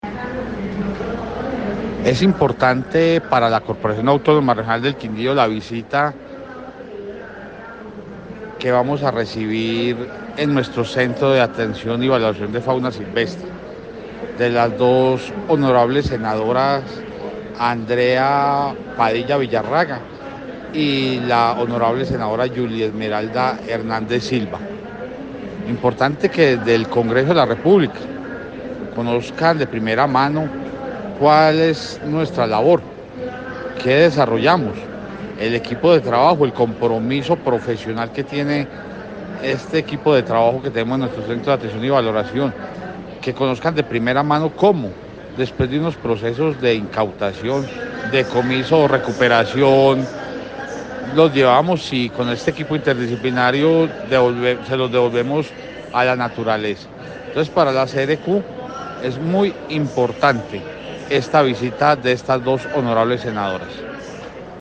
Audio José Manuel Cortés Orozco, director general de la CRQ:
Audio-director-general-de-la-CRQ-Jose-Manuel-Cortes-Orozco.mp3